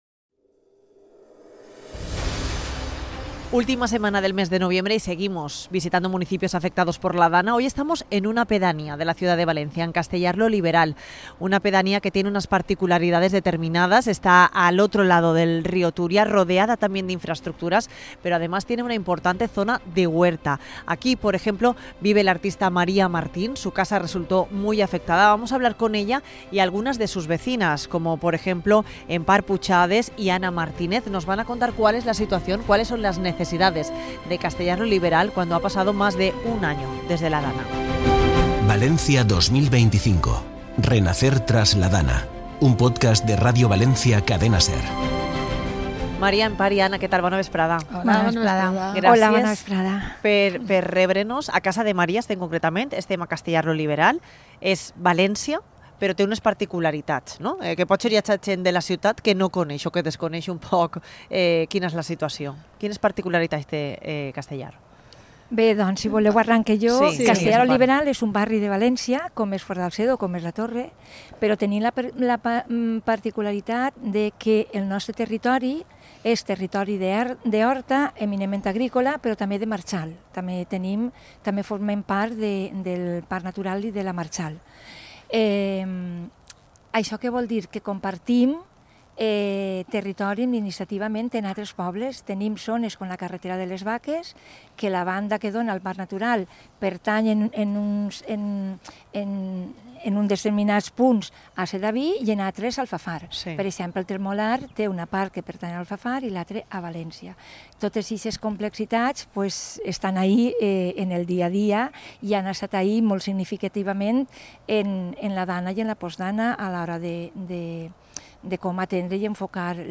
En el capítulo 48 de Valencia 2025: Renacer tras tras la DANA visitamos Castellar-Oliveral, pedanía de València, al otro lado del río Túria y también muy afectada por ese agua, barro, coches y suciedad que llegó del barranco del Poyo. El casco urbano resultó afectado pero también zonas como donde grabamos este capítulo, en mitad de la huerta.